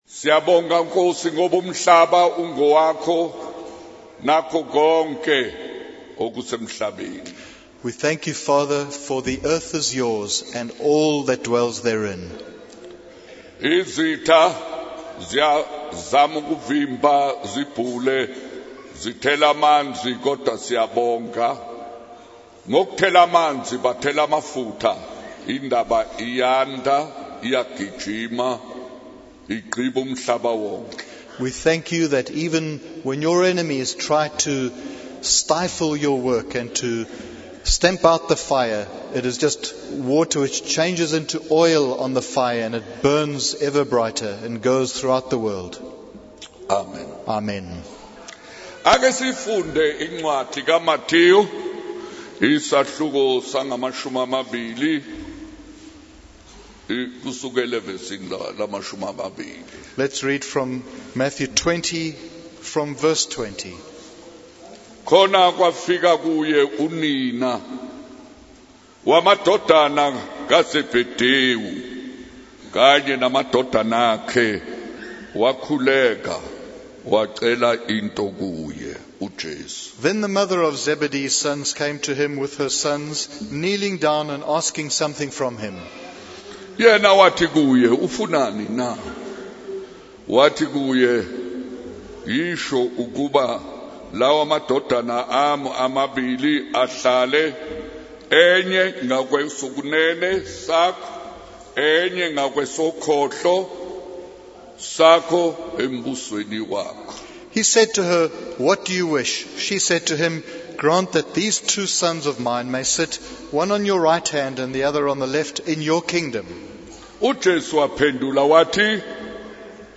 In this sermon, Jesus addresses the issue of power and authority among his disciples.